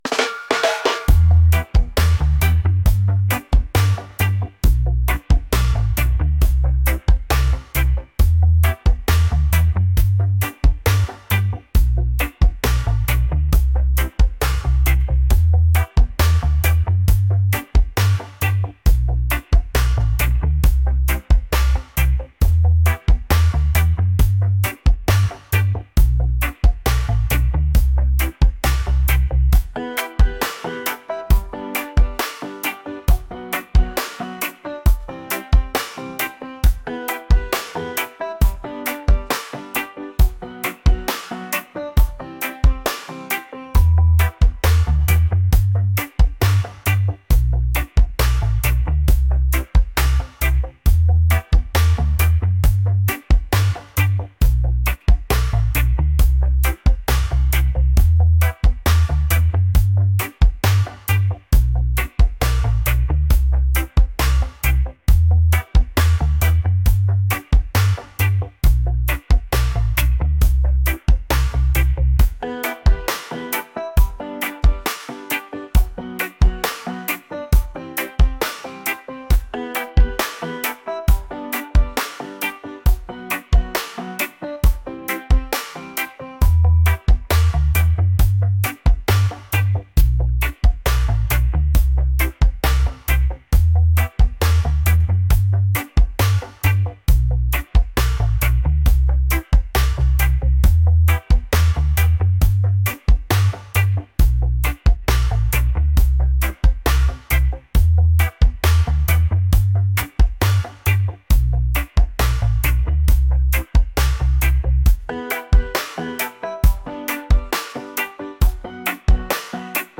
upbeat | reggae